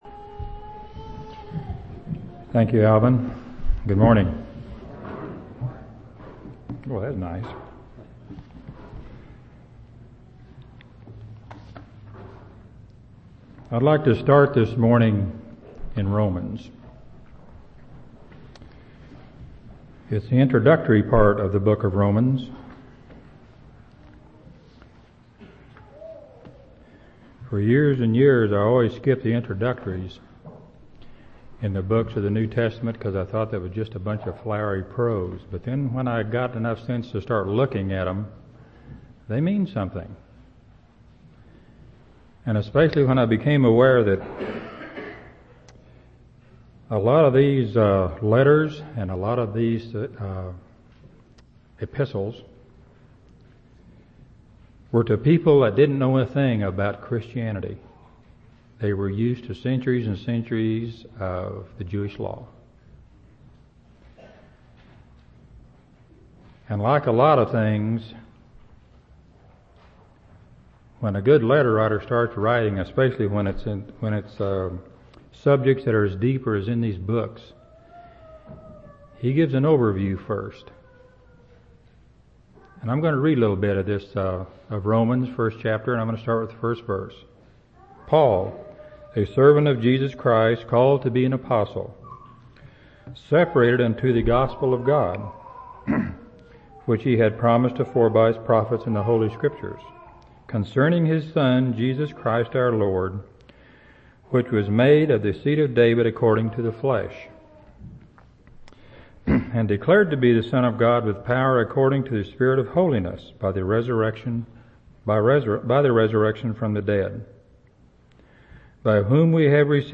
3/23/2003 Location: Temple Lot Local Event